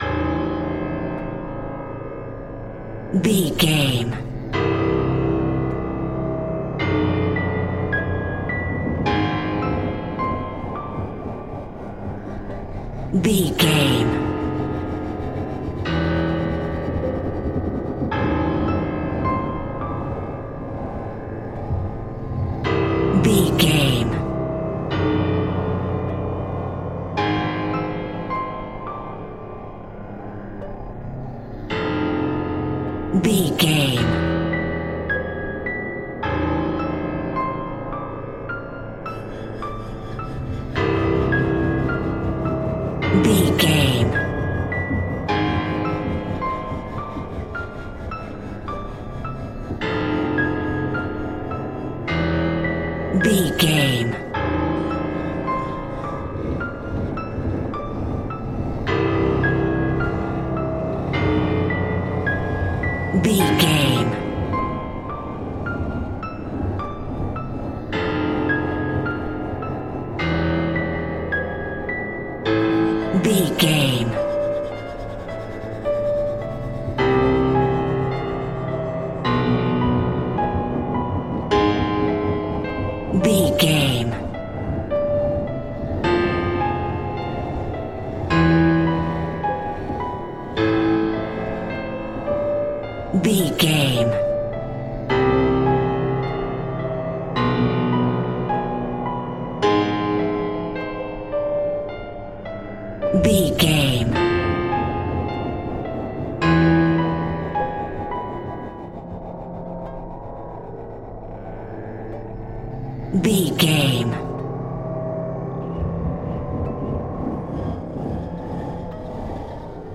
Survival horror
Aeolian/Minor
scary
tension
ominous
dark
haunting
eerie
piano
horror
synth
pads
eletronic